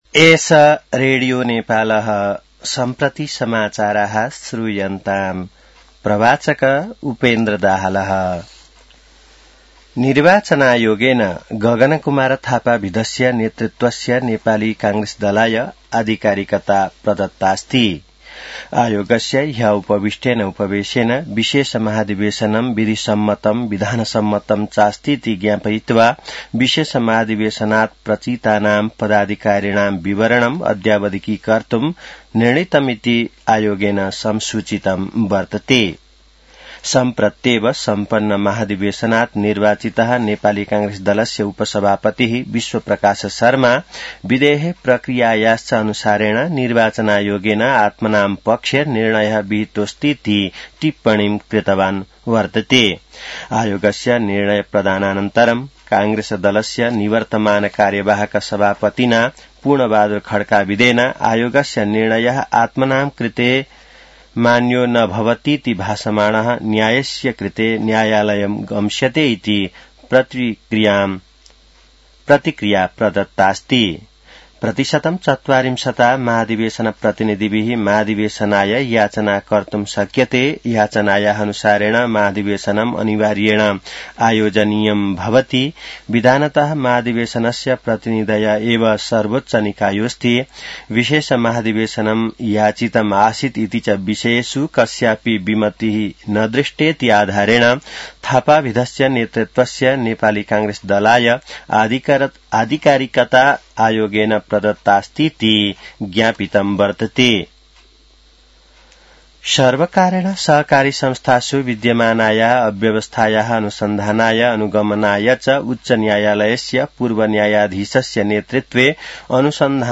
संस्कृत समाचार : ३ माघ , २०८२